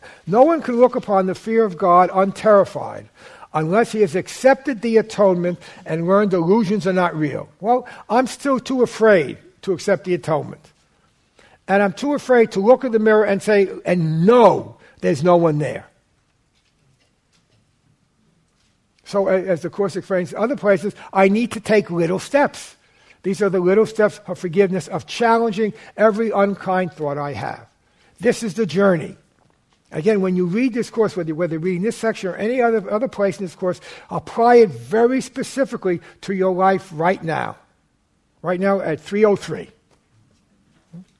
The statement from the text “Those you do not forgive you fear” (T-19.IV-D.11:6) was the basis of this 2012 seminar that focused on the holy instant, wherein we decide to forgive our special love and hate partners. Repeated emphasis was placed on making the choice for Jesus and his principle of forgiveness, right now.